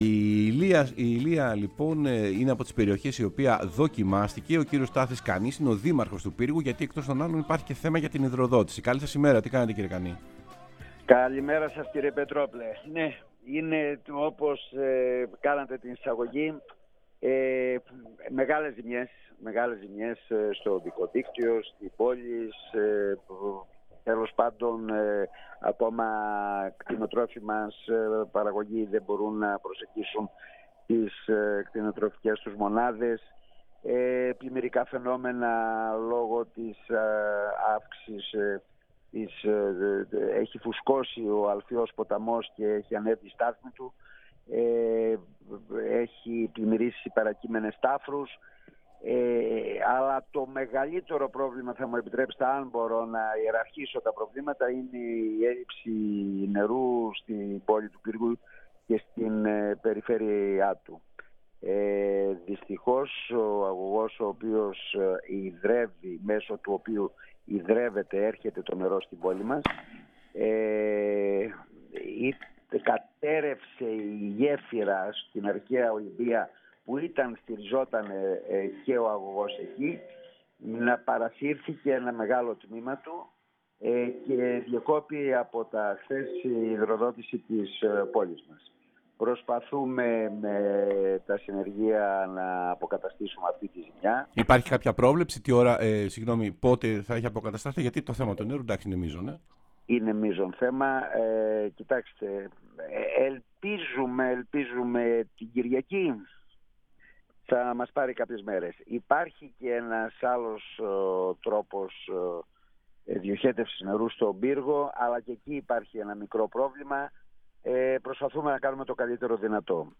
Στάθης Καννής, Δήμαρχος Πύργου, μίλησε στην εκπομπή «Σεμνά και Ταπεινά»